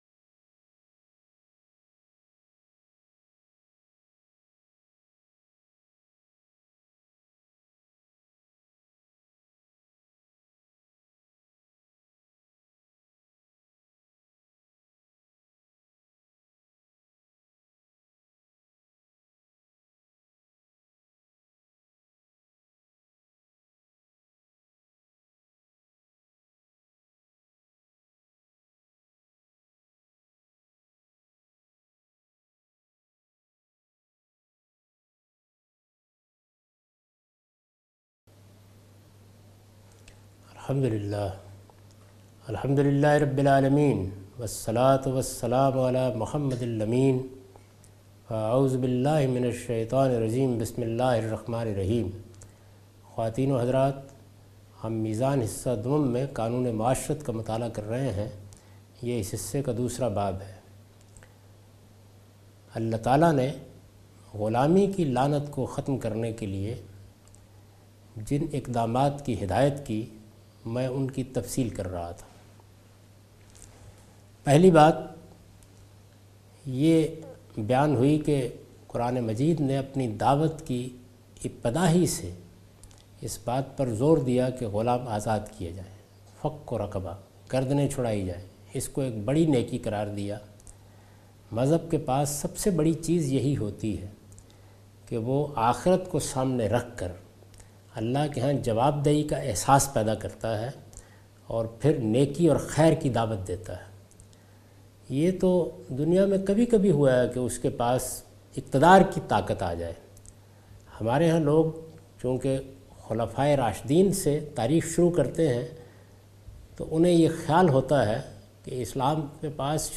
In this lecture he teaches the ruling of slavery in Islam.